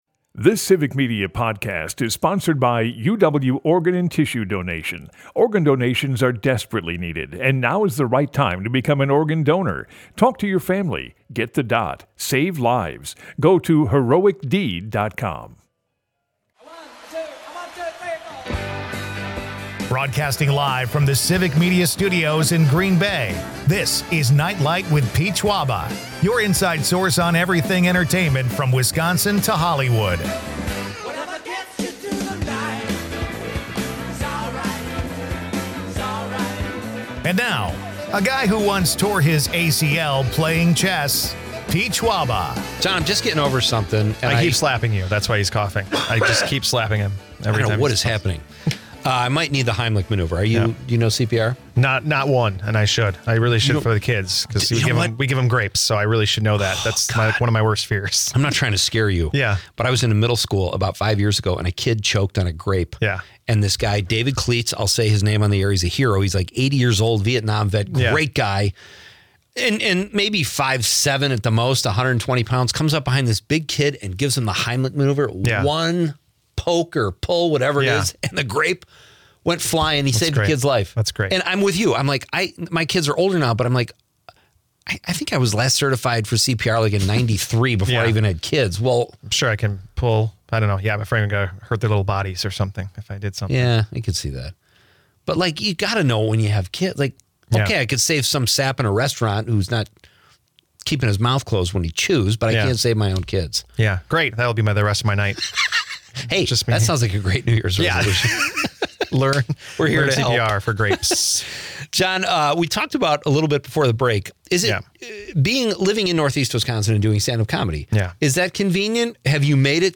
radio network